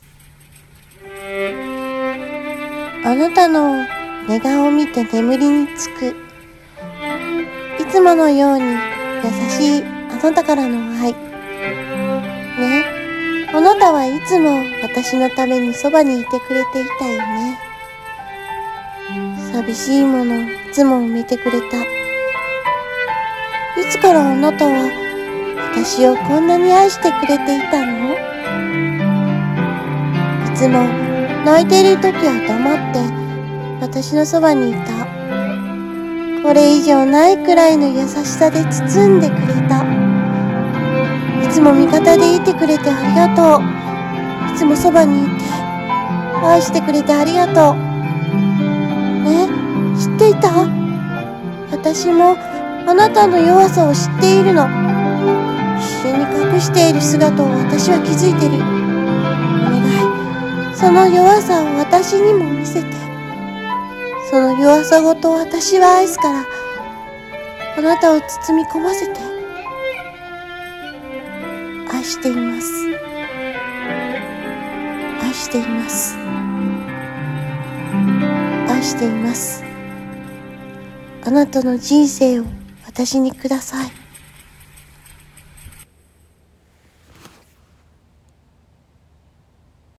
恋愛1人声劇【あなたへのボイスラブレター】